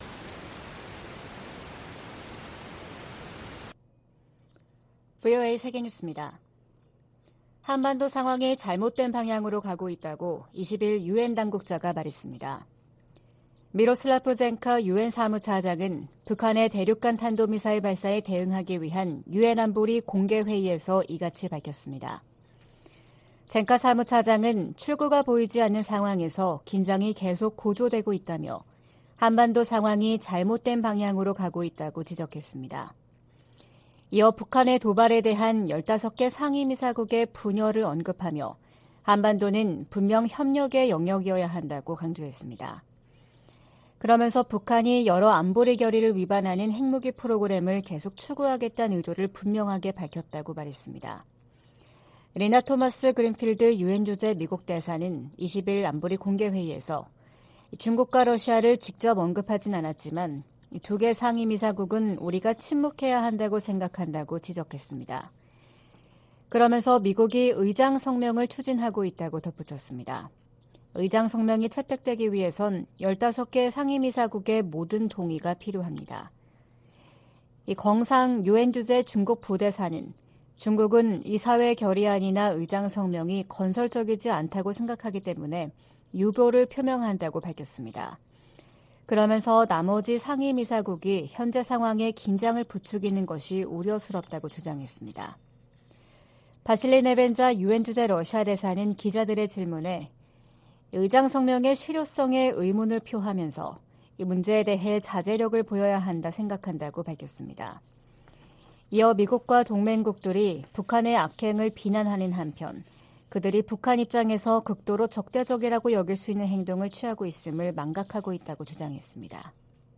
VOA 한국어 '출발 뉴스 쇼', 2023년 3월 22일 방송입니다. 북한이 모의 핵탄두를 탑재한 미사일 공중폭발 시험훈련에 성공했다고 밝히면서 전술핵 위협이 한층 현실화했다는 평가가 나옵니다. 유엔 안전보장이사회가 북한의 대륙간탄도미사일(ICBM) 발사에 대응한 공개회의를 개최하고 북한을 규탄했습니다. 북한에서 살인과 고문, 인신매매 등 광범위한 인권 유린 행위가 여전히 자행되고 있다고 미 국무부가 밝혔습니다.